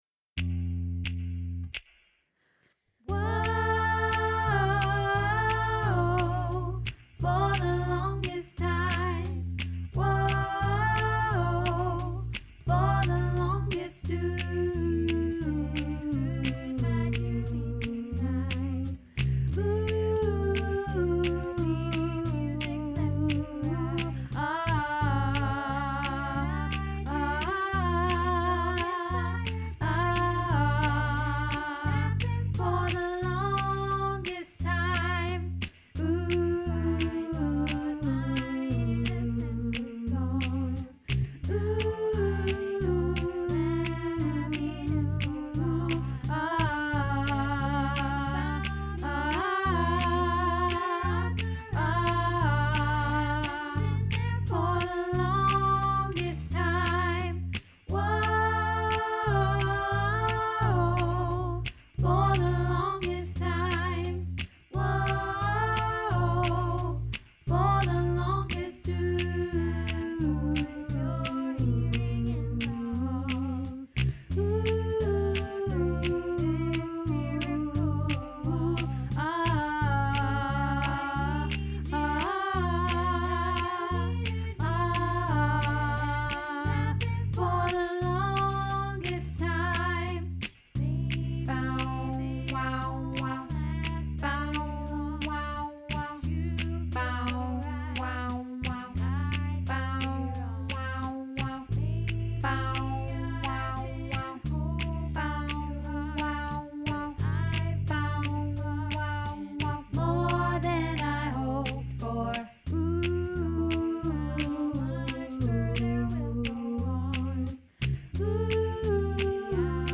The Longest Time Tenor